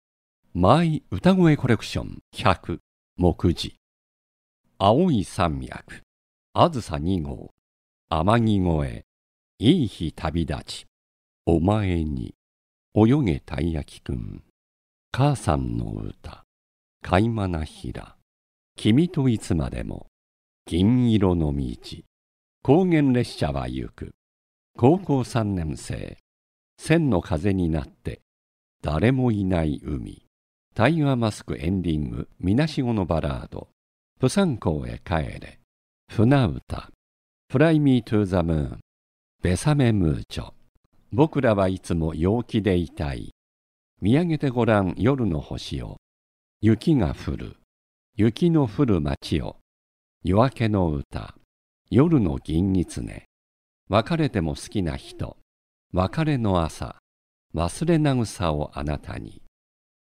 声の達人男性ナレーター
落ち着いた／穏やか
やさしい
アルト／重厚感
低音ボイスから高音ボイスまで自在に対応致します。
【ナレーターボイスサンプル】
ドキュメンタリー・ニュース・癒し系を得意とし、バラエティーや台詞にも対応します。